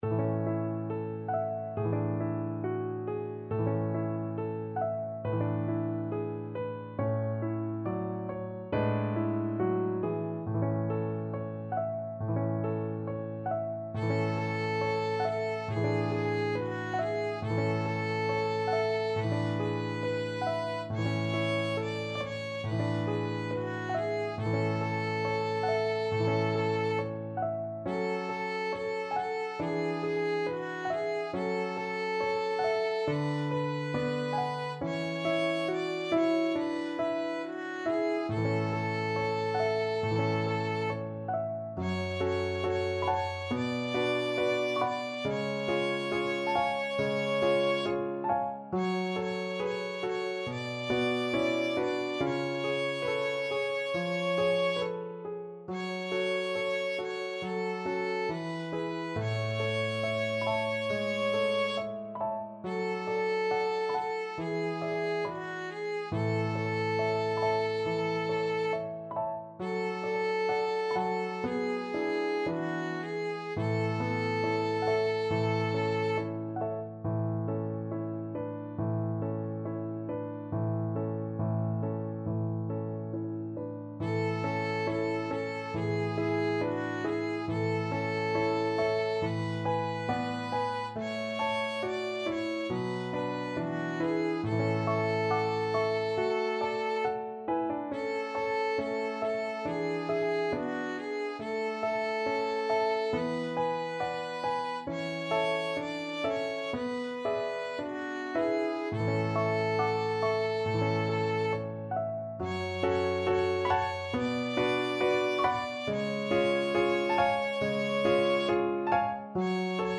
Violin
A major (Sounding Pitch) (View more A major Music for Violin )
4/4 (View more 4/4 Music)
~ = 69 Andante tranquillo
hine_e_hine_VLN.mp3